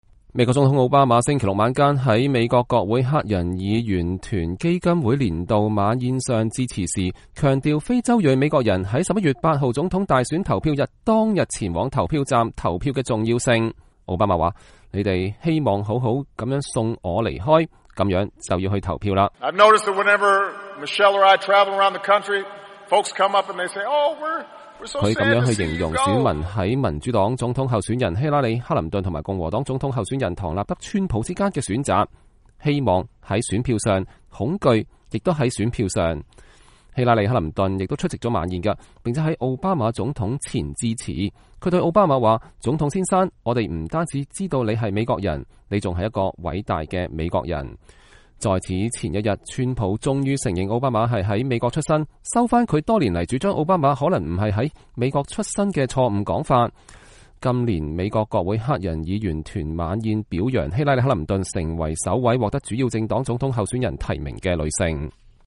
美國總統奧巴馬星期六晚間在美國國會黑人議員團基金會年度晚宴上致辭時，強調非洲裔美國人在11月8日總統大選投票日當天前往投票站投票的重要性。